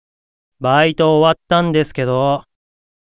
Leviathan_Job_Notification_Voice.ogg.mp3